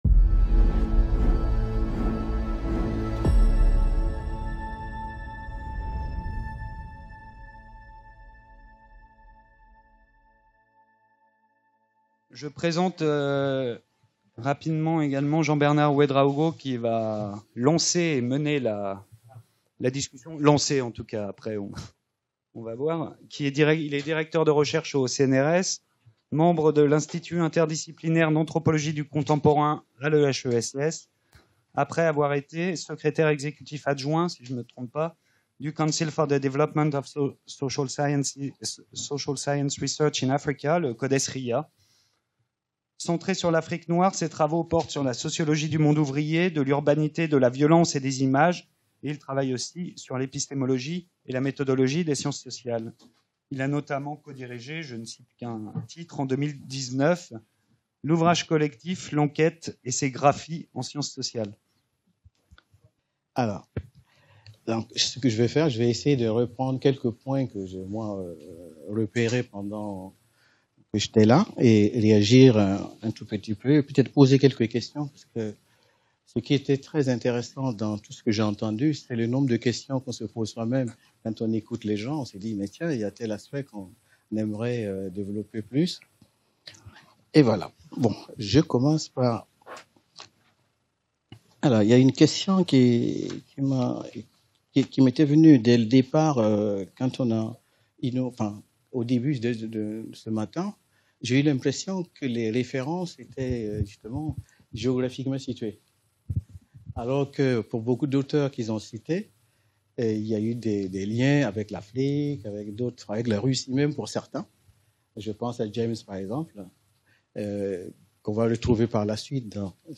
Cette journée d’études est une première tentative pour (re)lancer le débat en adoptant une approche croisée entre les Caraïbes “britannique” et “française” et en élargissant la perspective aux transformations des modes et rapports de production, des formes de travail et de résistance, après les abolitions jusqu’à l’ère néolibérale dans laquelle nous vivons. Il s’agit en définitive d’encourager le développement d’une critique de l’économie politique qui reste le parent pauvre des études contemporaines sur la Caraïbe dans le monde francophone.